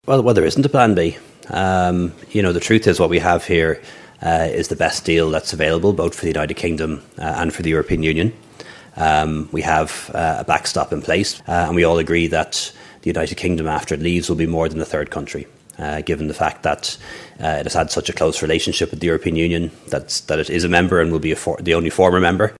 That’s according to the Taoiseach who was speaking after the agreement was signed off today.
The Taoiseach added that there isn’t a fallback plan should this agreement fail to pass in the House of Commons: